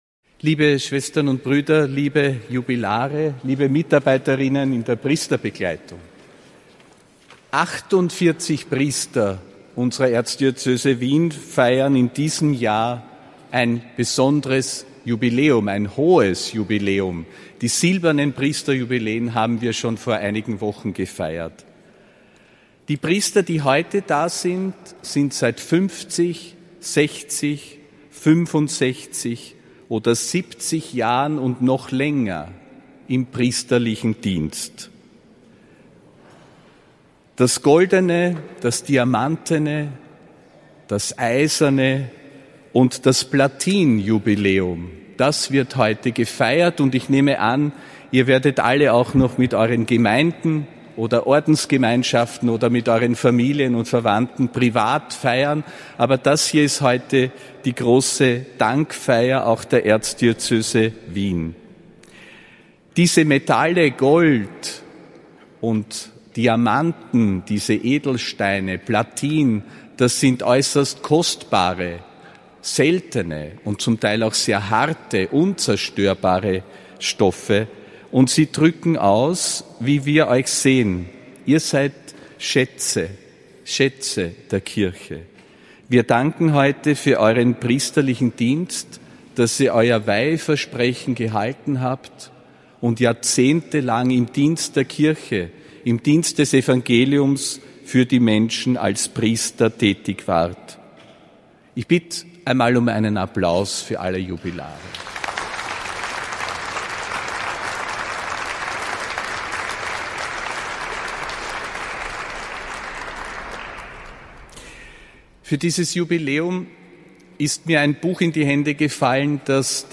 Predigt des Apostolischen Administrators Josef Grünwidl beim Festgottesdienst mit den 60 jährigen und goldenen Priesterjubilaren der Erzdiözese Wien, am 27. Mai 2025.